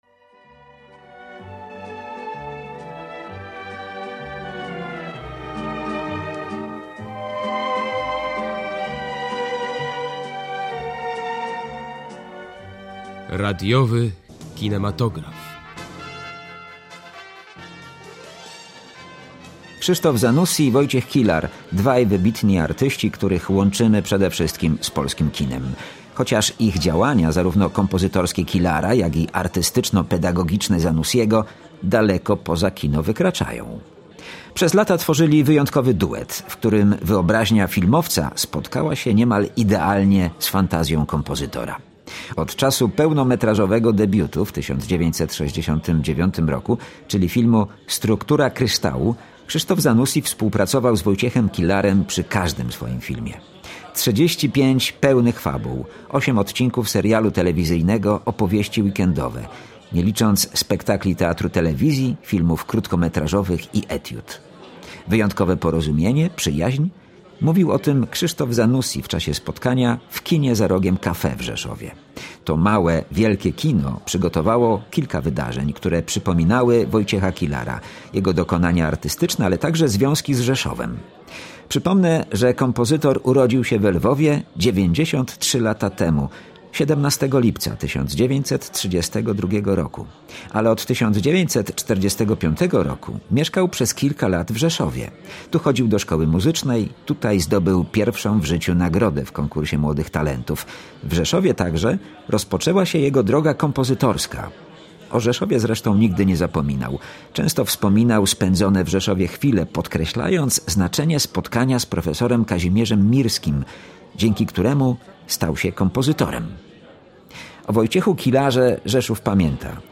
– Wyjątkowe porozumienie, jednomyślność, przyjaźń – mówił o tym Krzysztof Zanussi, w czasie spotkania w ‘’Kinie za Rogiem Cafe’’ w Rzeszowie.